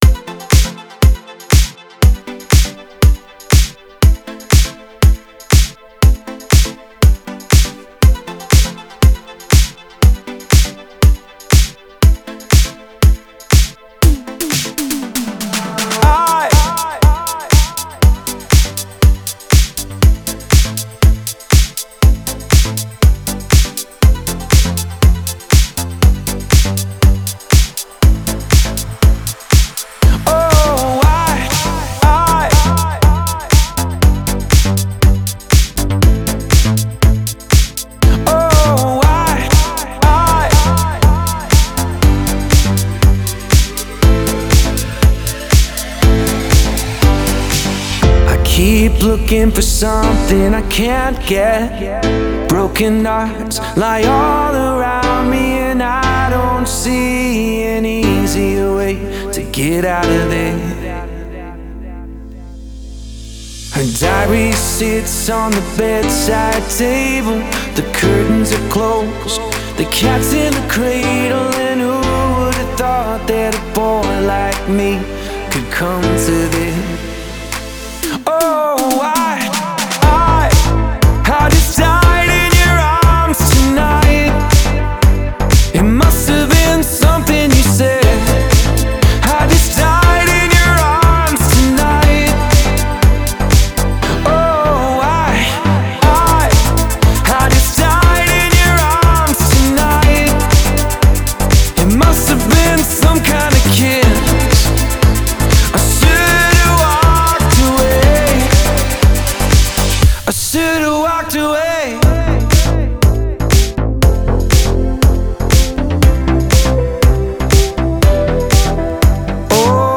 Стиль: Dance / Pop / Club House / Vocal House